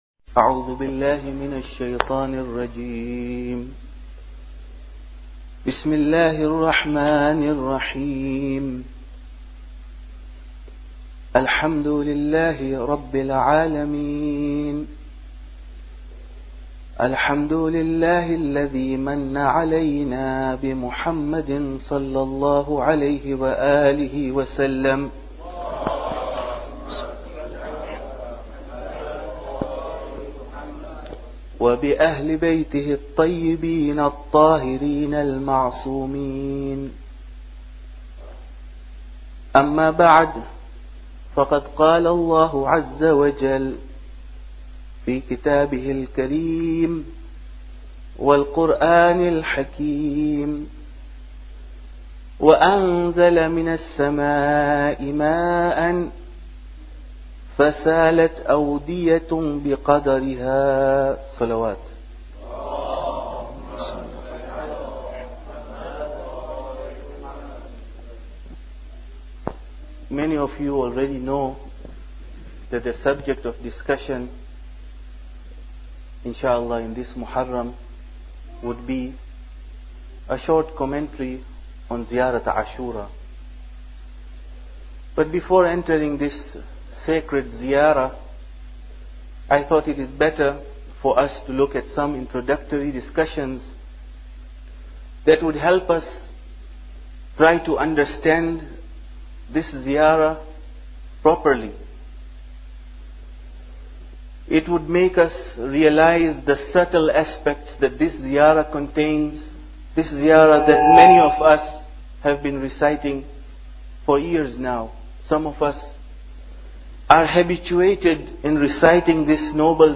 Muharram Lecture 1